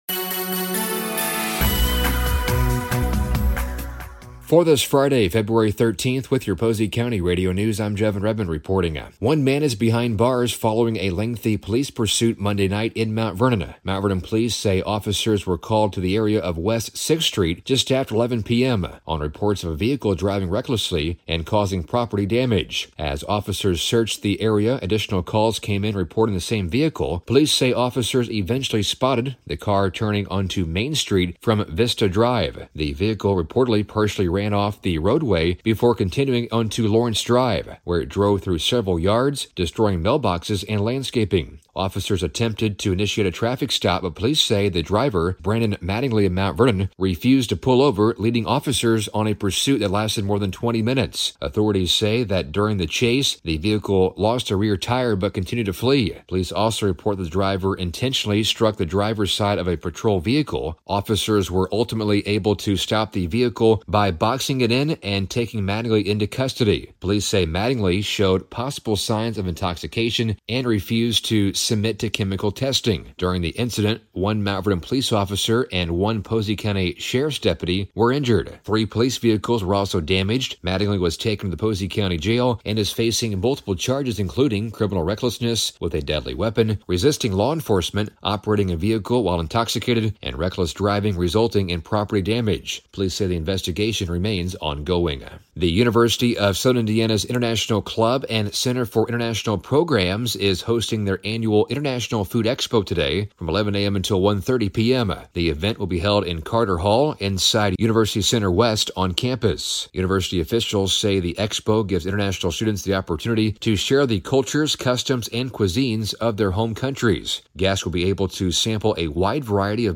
Local News: Friday February 13th, 2026